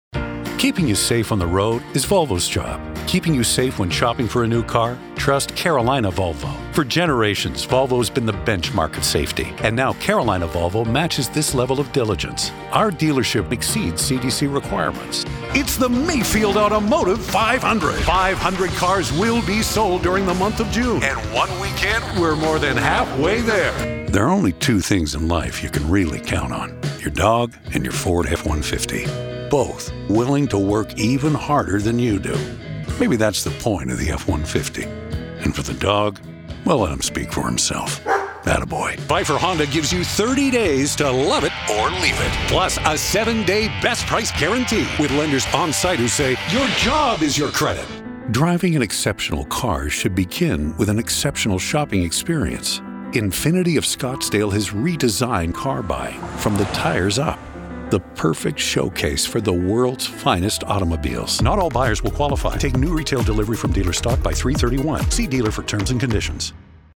Radio Imaging, Documentary, Corporate, Automotive, and TV Promos.
automotive